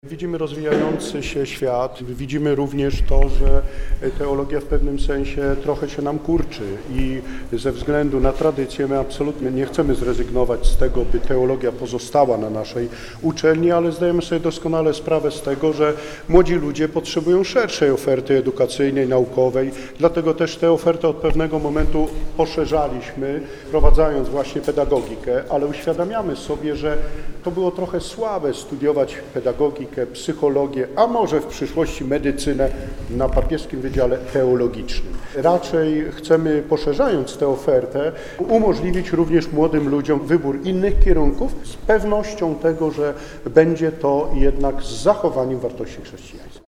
Briefing ogłaszający powstanie Akademii Katolickiej we Wrocławiu, 27.01.2026